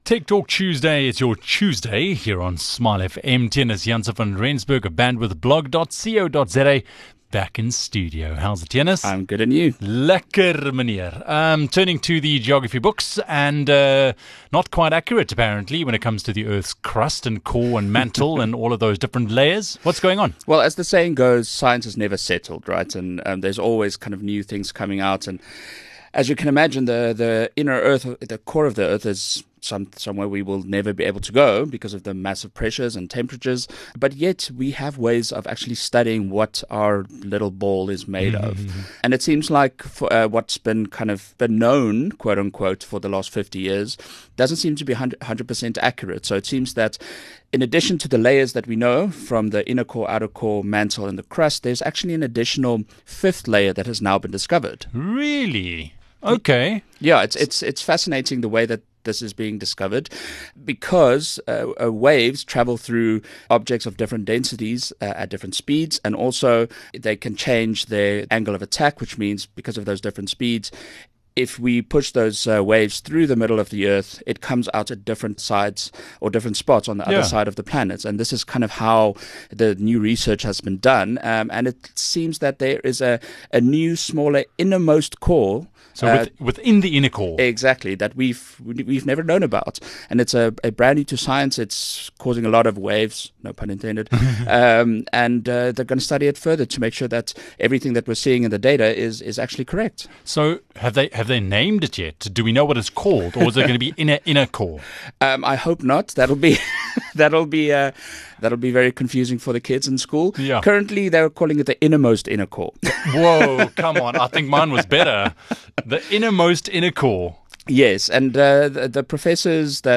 In my Tech Tuesday chat